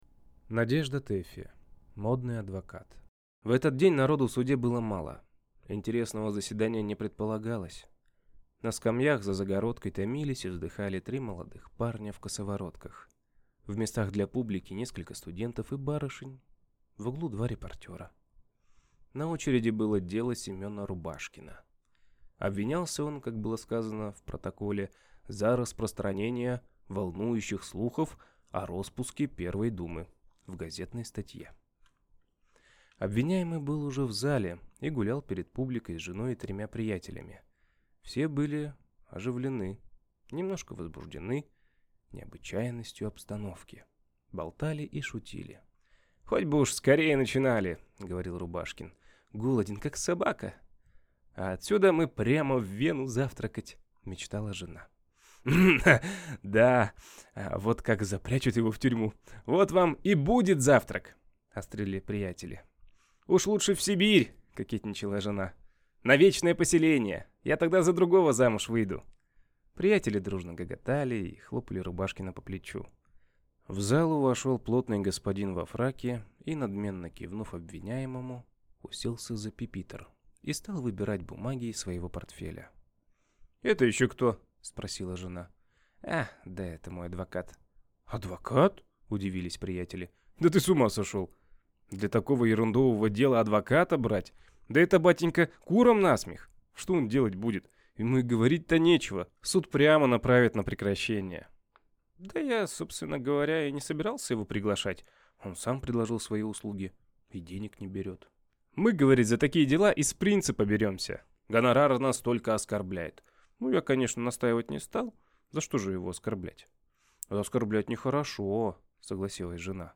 Аудиокнига Модный адвокат | Библиотека аудиокниг